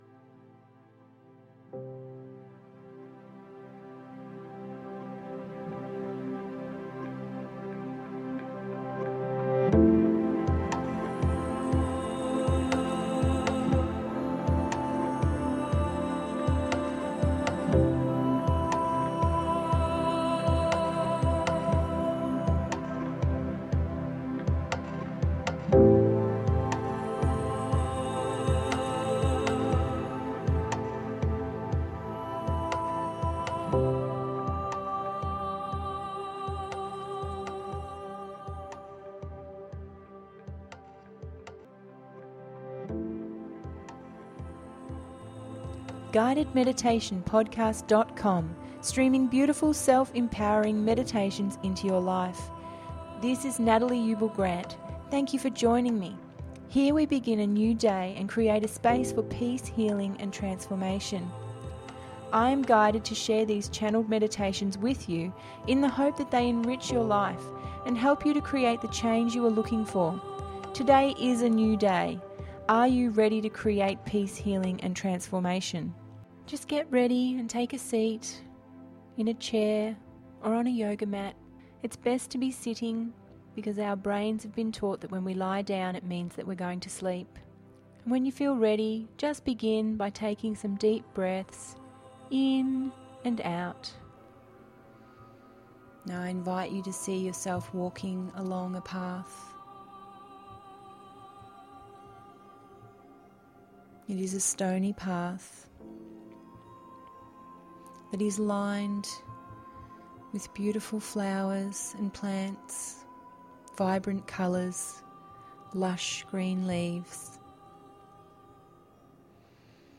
Tranquil Waterfall…ep 18 – GUIDED MEDITATION PODCAST